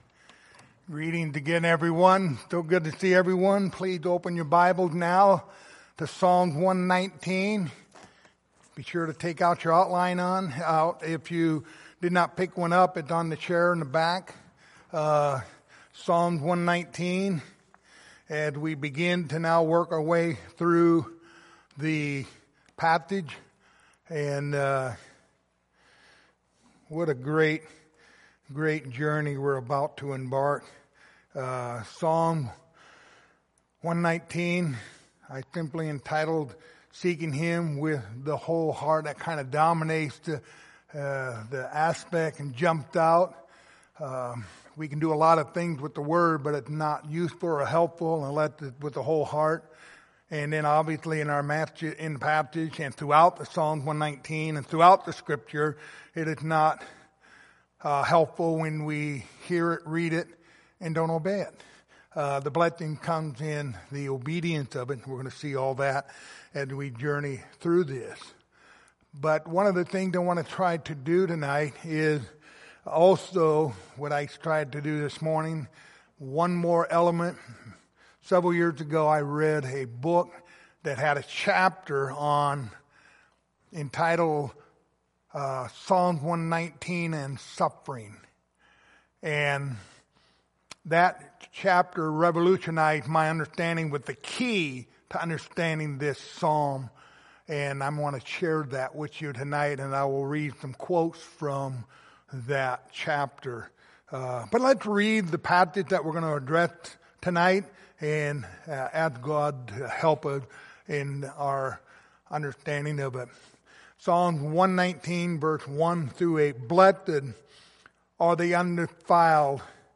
Passage: Psalms 119:1-8 Service Type: Sunday Evening